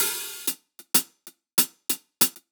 Index of /musicradar/ultimate-hihat-samples/95bpm
UHH_AcoustiHatA_95-01.wav